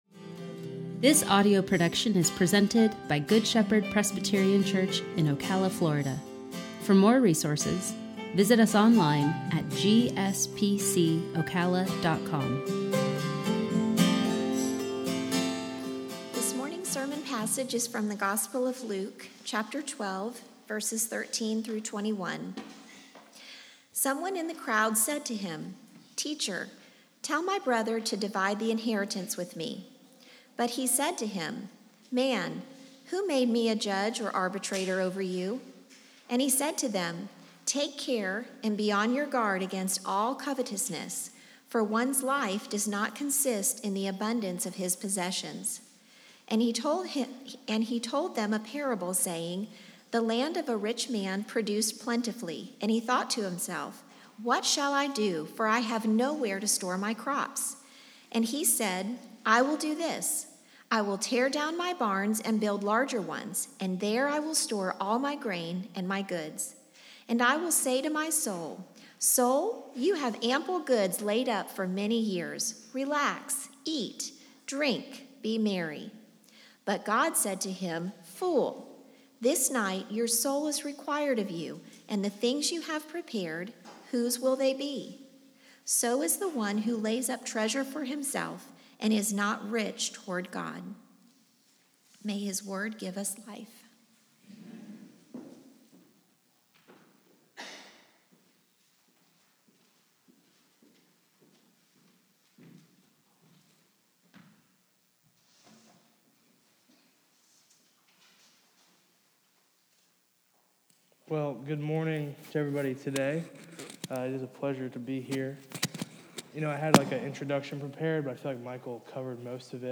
sermon-1-24-21.mp3